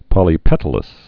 (pŏlē-pĕtl-əs)